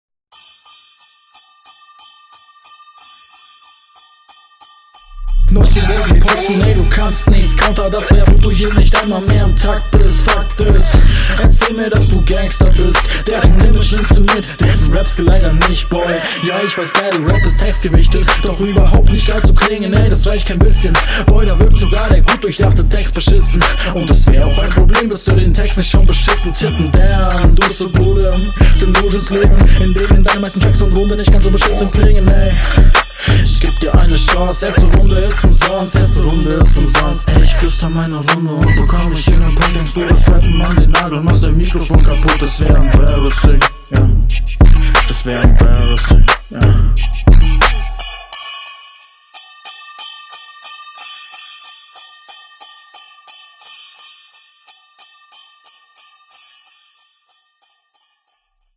Big respect das das alles gefreestyled ist!